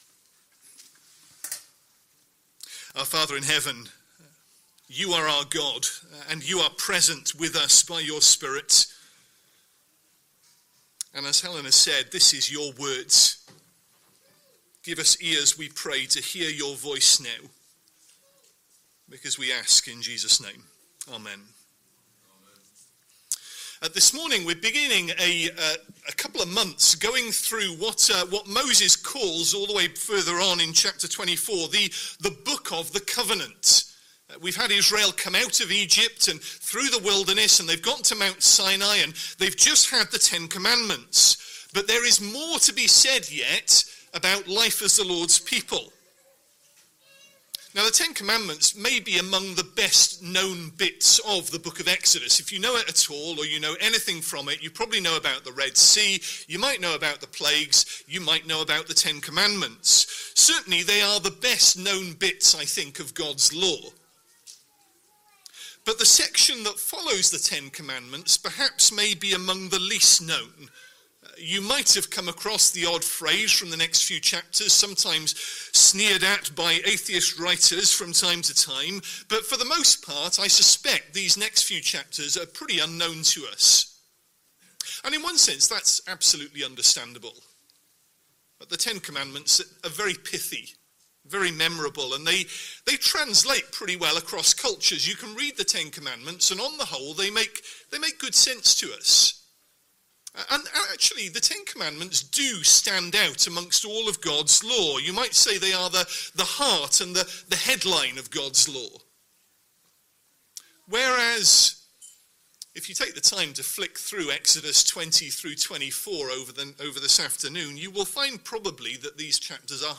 Sunday Evening Service Speaker